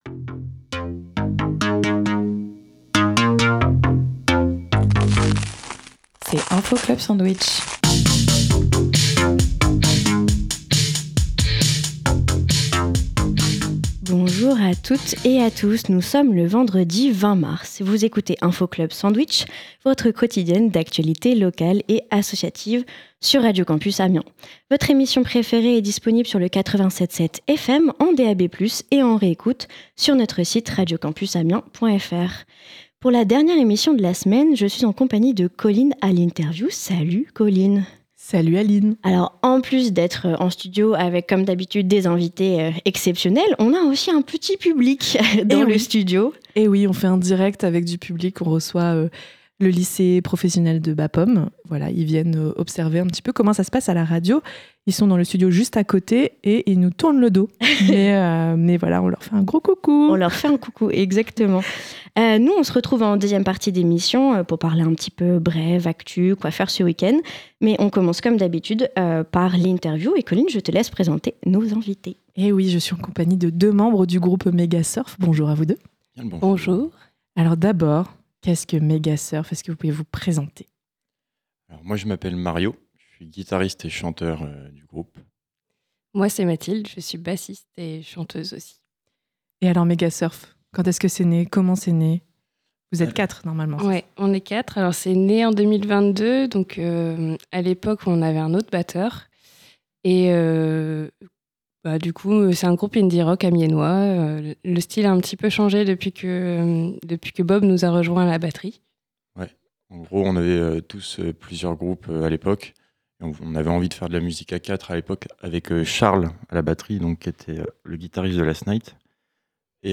Dans la quotidienne de ce vendredi, on reçoit le groupe de musique Megasurf.